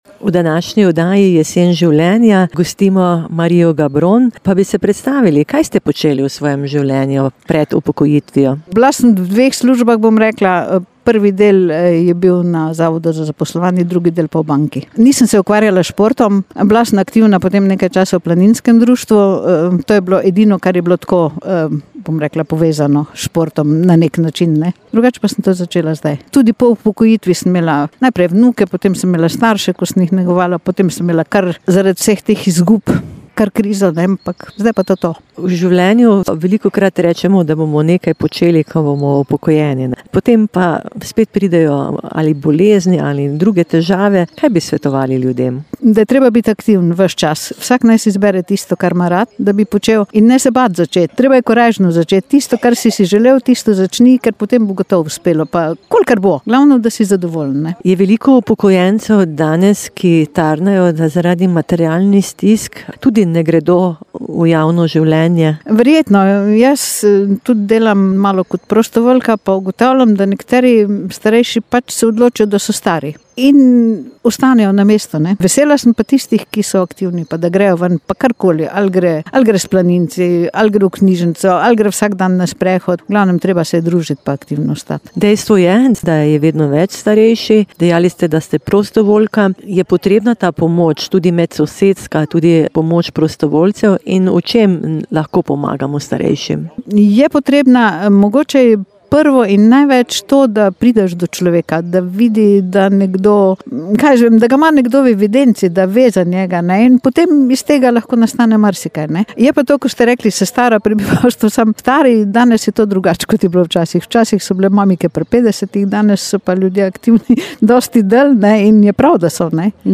"Jesen življenja" - oddaja ob 18. uri, pogovor z